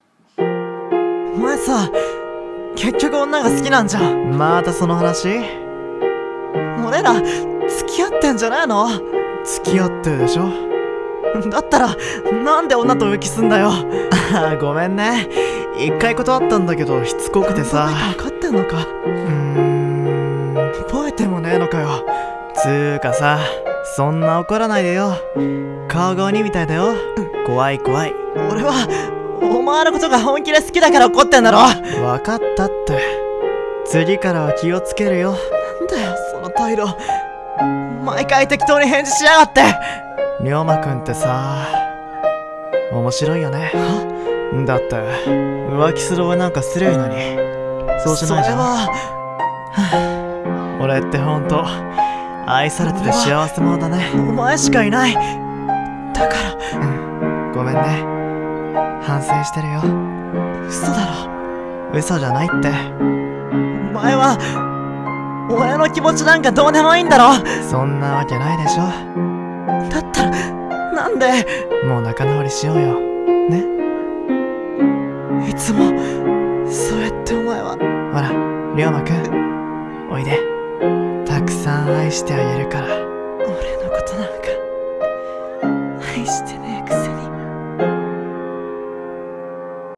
【BL声劇】何度裏切られても·····【二人声劇】